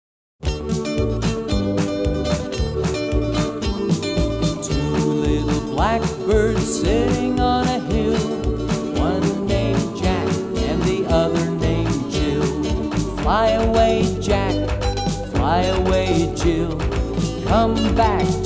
Traditional Children's Fingerplay Song with Actions